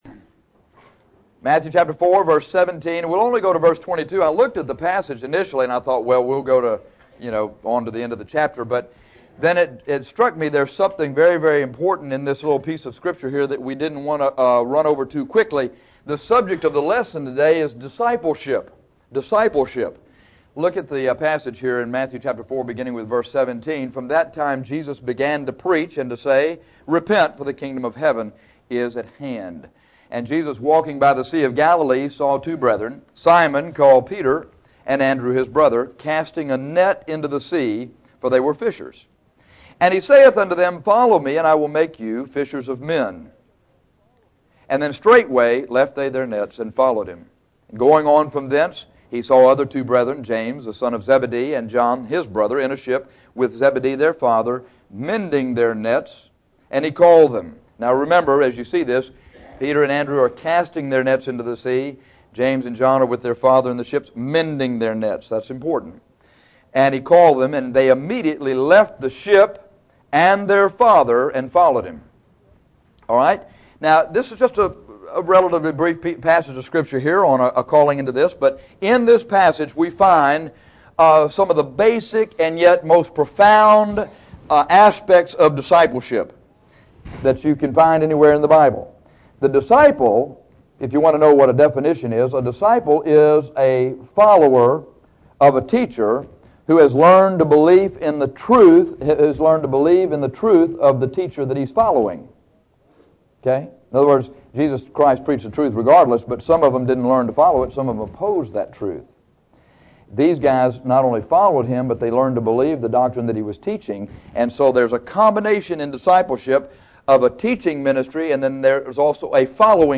In the first part of this lesson, we see the “teaching” of discipleship an in the second part, the “following” of discipleship.